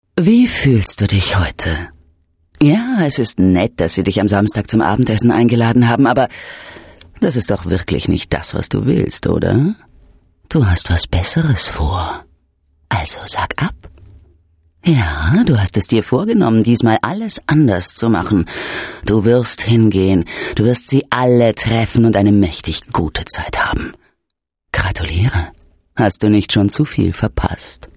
Unter den Sprecherinnen in Deutschland war Franziska Pigulla diejenige, mit der wohl tiefsten Sprecherinnenstimme. Ihre warme, einzigartige Stimme und Sprechweise gaben allen Produktionen etwas sehr Spezielles, mit einem 100-prozentigem Wiedererkennungswert, zahlreiche Marken erfolgreich als Werbestimme nutzten.
H Ö R B E I S P I E L E – in der finalen Tonmischung: